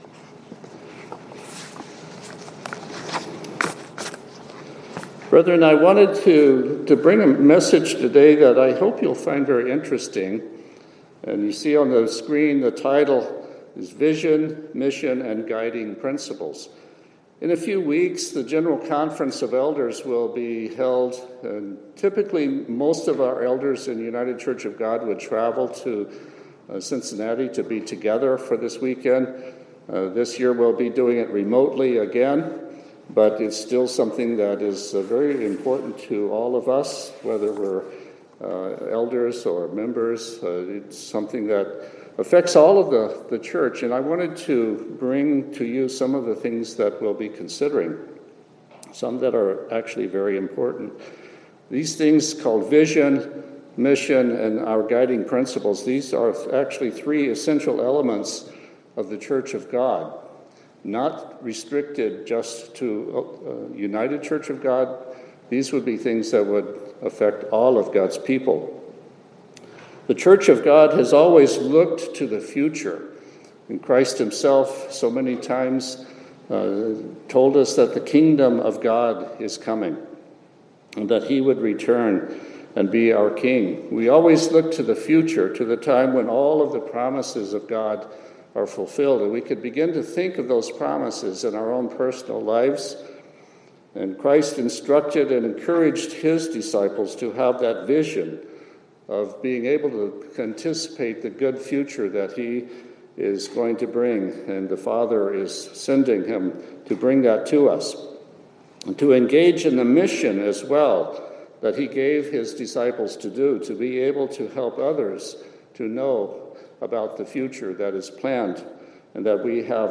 Sermons
Given in Olympia, WA Tacoma, WA